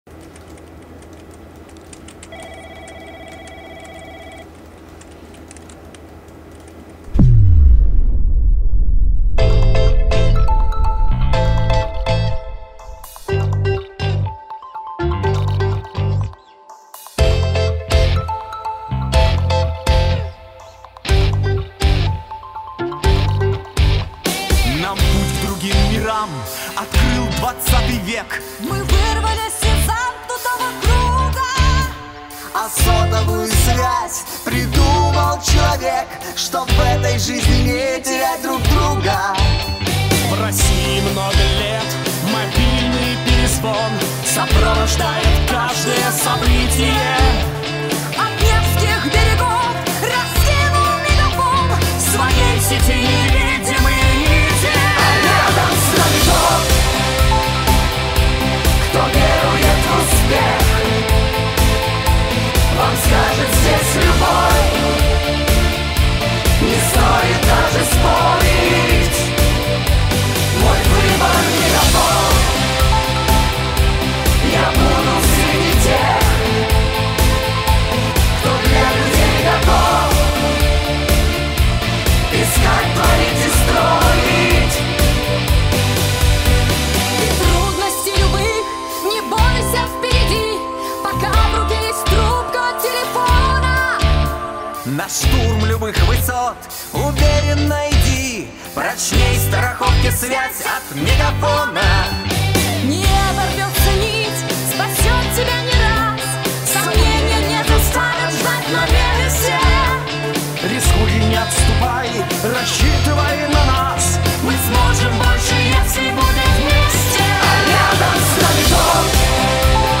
обновленная версия с новым звучанием